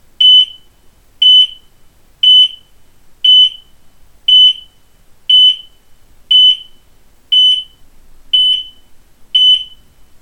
Wie in unseren Audio-Files hörbar, werden Rauchalarm und Hitzealarm dabei durch unterschiedliche Alarmtöne signalisiert.
Alarm bei Hitze-Detektion:
abus-rwm450-hitzealarm.mp3